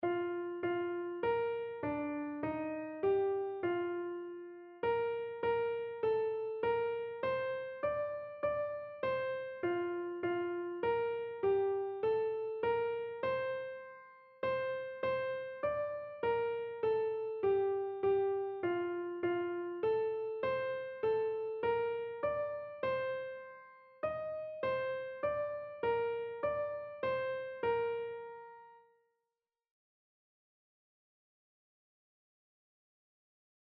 Einzelstimmen (Unisono)
• Sopran [MP3] 528 KB